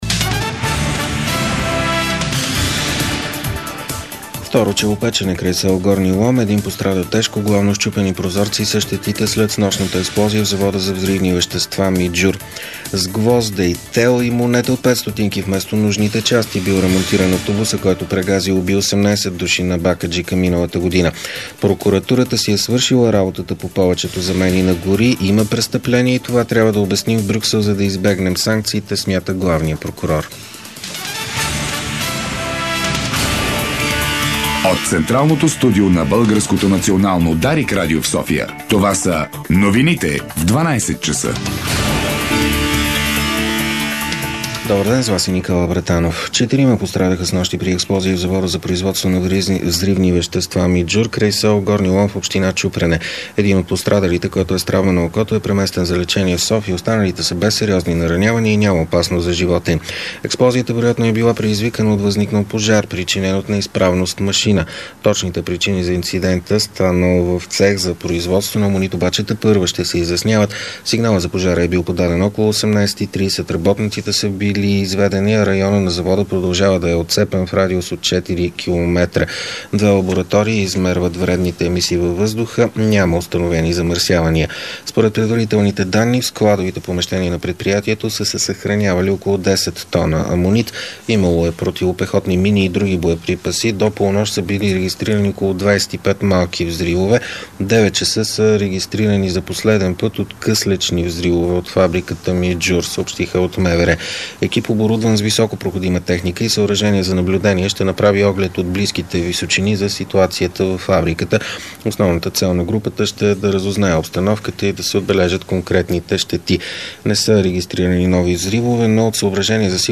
Обедна информационна емисия - 04.02.2010